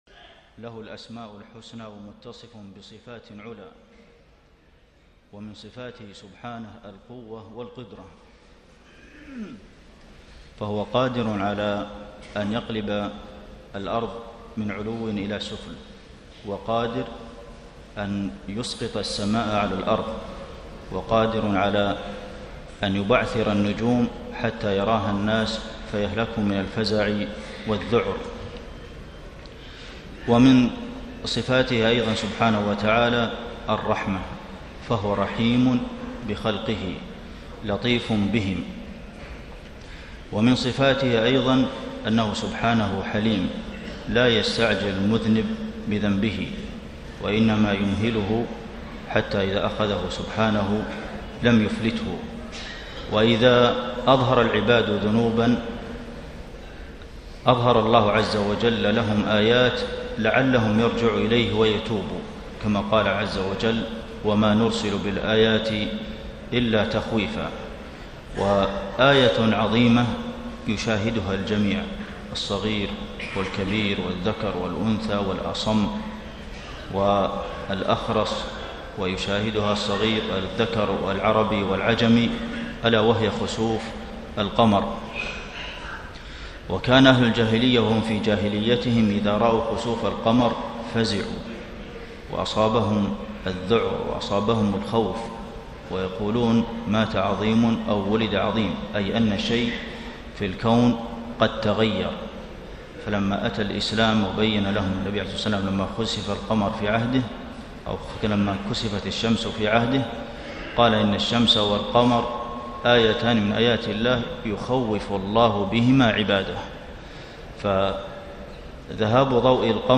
كلمة الشيخ عبدالمحسن القاسم بعد صلاة الخسوف ١٥ محرم ١٤٣٣ > كلمات أئمة الحرم النبوي 🕌 > المزيد - تلاوات الحرمين